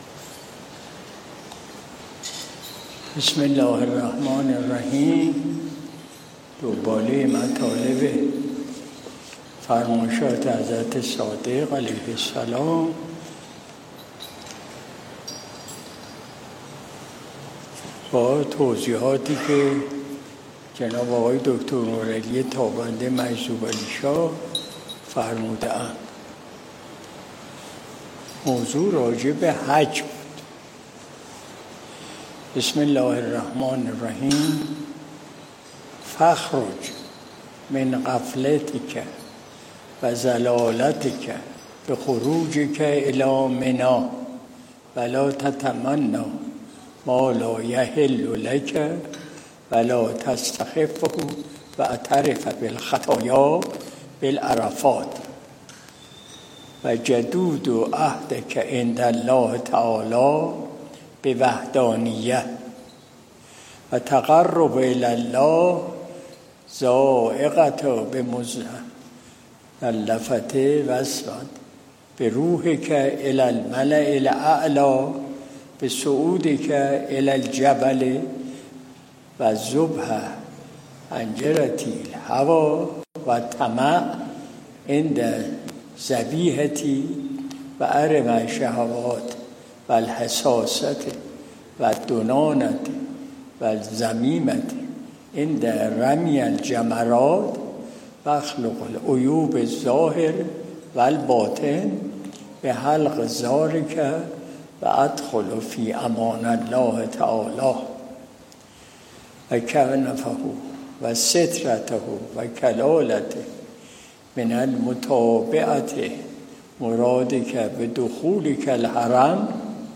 مجلس شب دوشنبه ۱۴ خرداد ماه ۱۴۰۲ شمسی